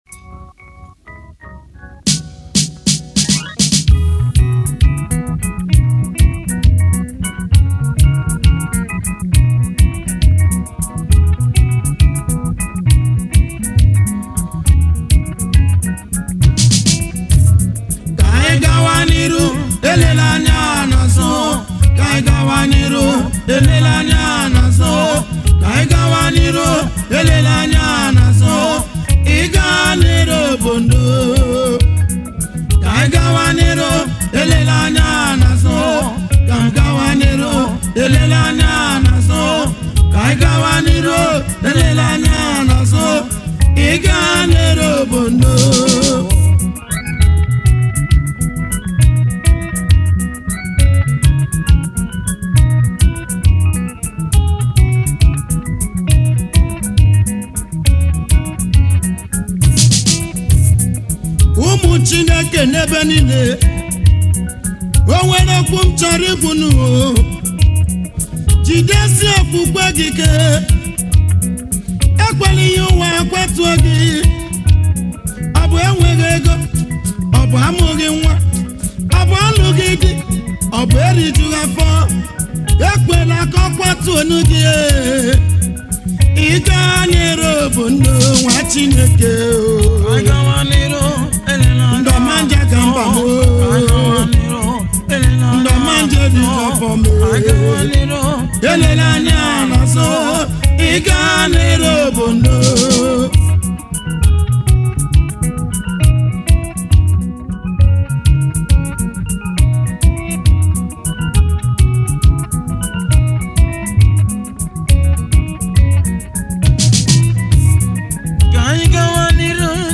January 22, 2025 Publisher 01 Gospel 0